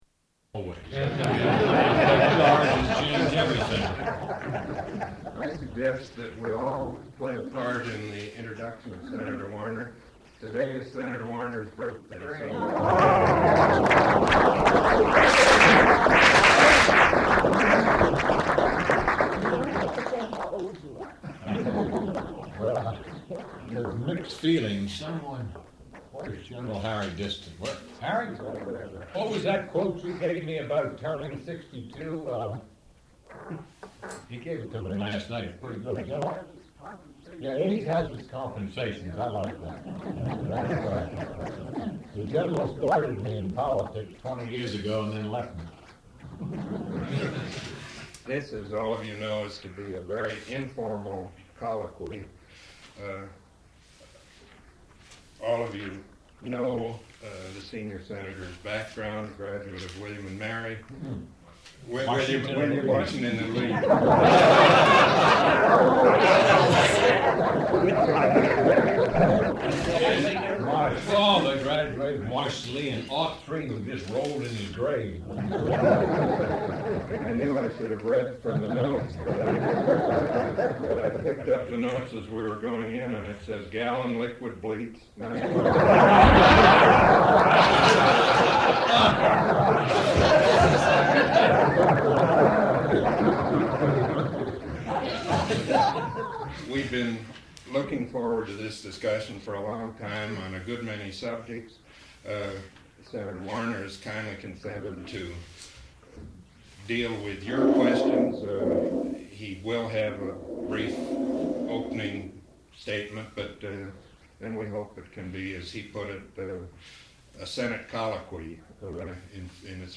Questions and Answers | Miller Center
The first two minutes of this Forum are slightly distorted.